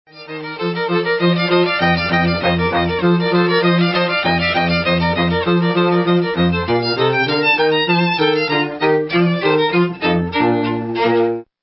Violinist
Wedding Ceremony and Prelude